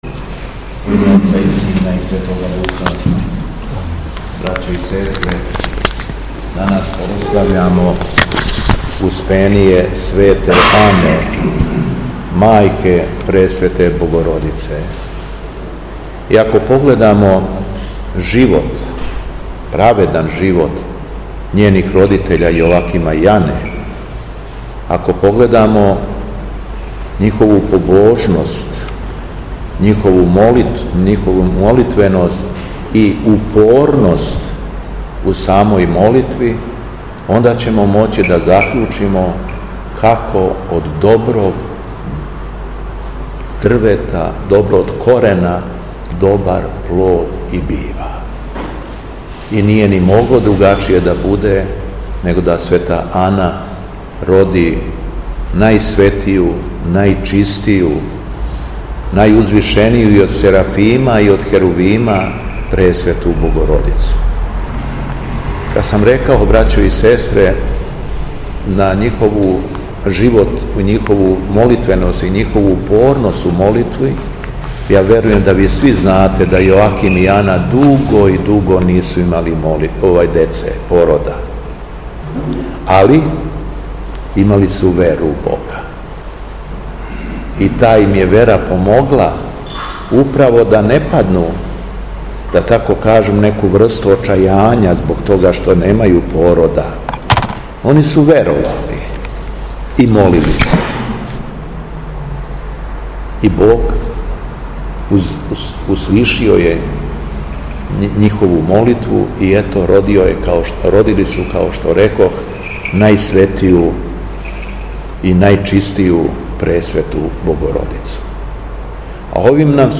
Беседа Његовог Високопреосвештенства Митрополита шумадијског г. Јована
Митрополит Јован се након прочитаног Јеванђеља по Луки обратио сабраном народу:
Дана 7. августа 2024. године, на празник Успења Свете Ане, мајке Пресвете Богородице, Високопреосвећени Митрополит шумадијски Господин Јован началствовао је свештеним евхаристијским сабрањем у крагујевачком насељу Белошевац у храму Светог великомученика цара Лазара Косовског.